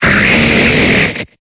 Cri de Libégon dans Pokémon Épée et Bouclier.